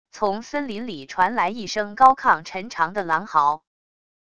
从森林里传来一声高亢沉长的狼嚎wav音频